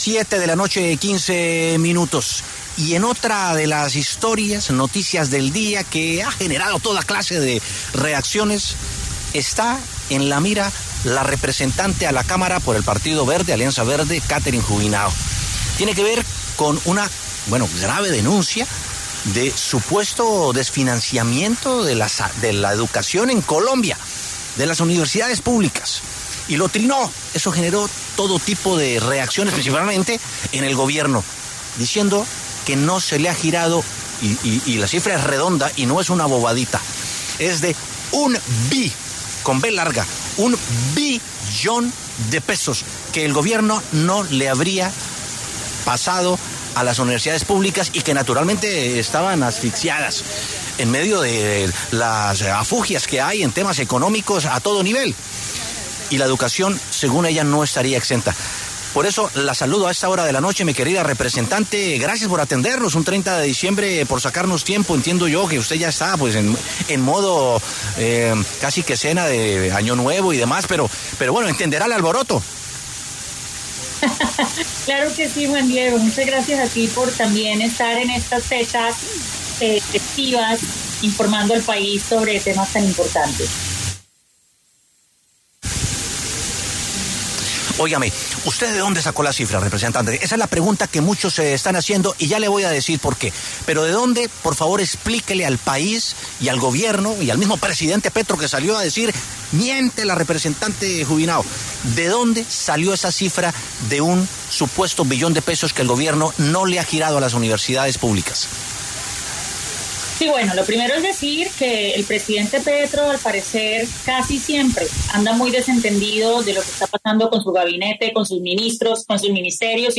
Catherine Juvinao, representante a la Cámara, habló en W Sin Carreta y explicó que la información que ella tenía estaba a corte del 13 de diciembre.